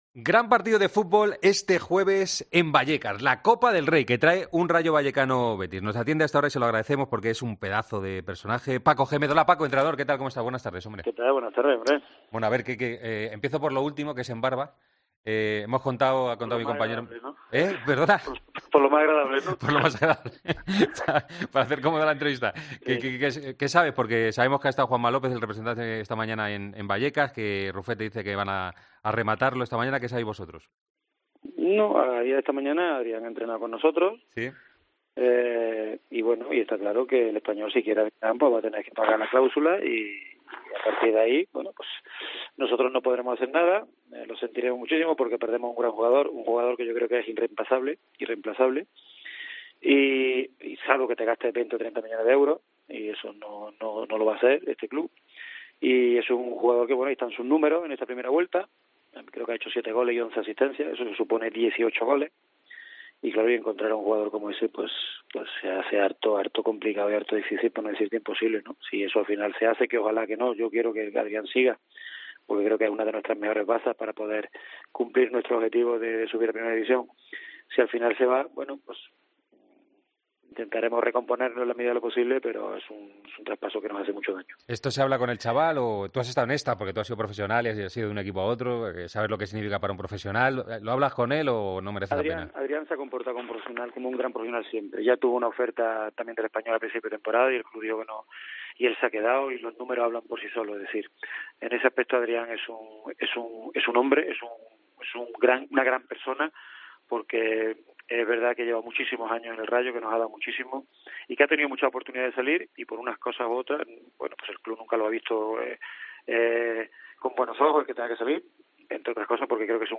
El técnico ha pasado por los micrófonos de COPE para analizar la situación del Rayo Vallecano: "Esperemos que en la segunda vuelta sople el viento a favor"